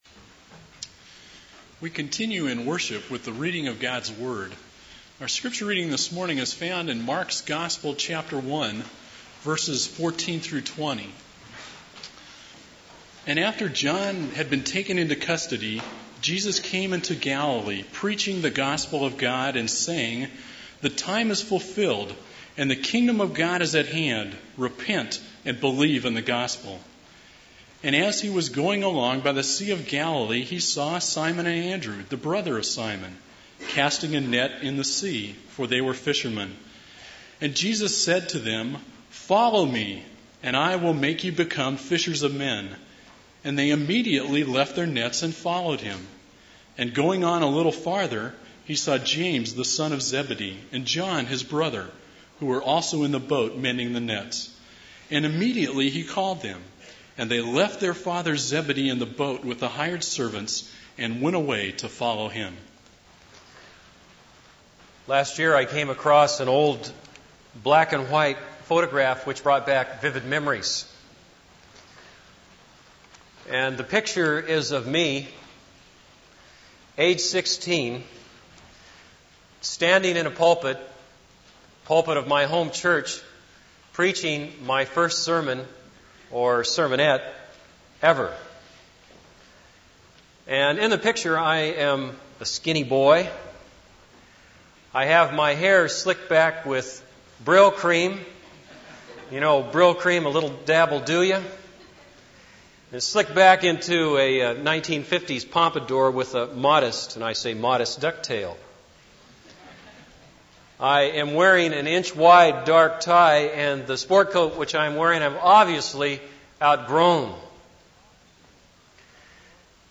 This is a sermon on Mark 1:14-20.